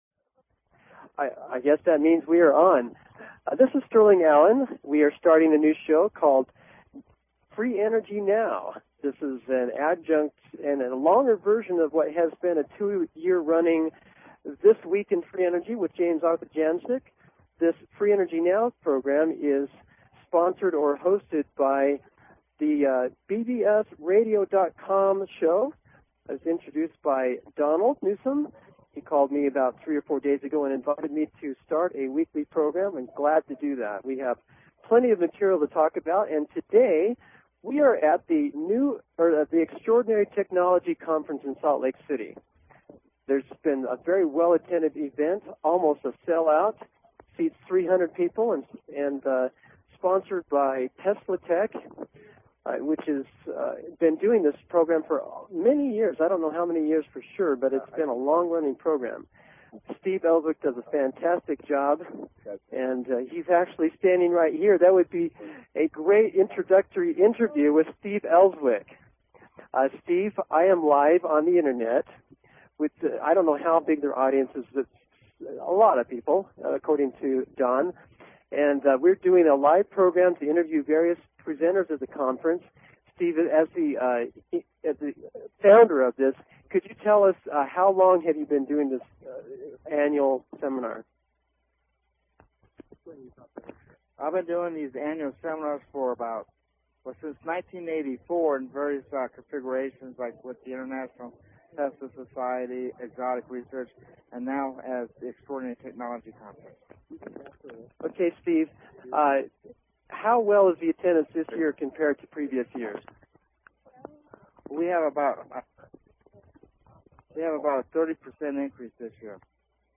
First broadcast featured live interviews from the ExtraOrdinary Technology Conference then under way in Salt Lake City.